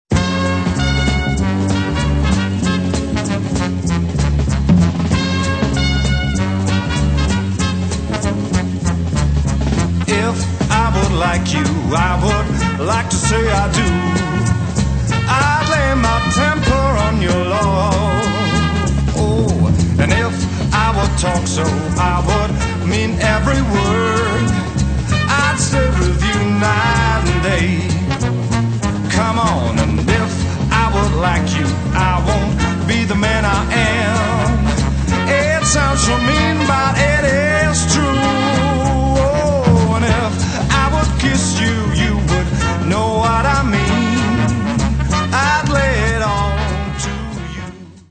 Das klingt doch nach tiefsten Siebziger Jahren!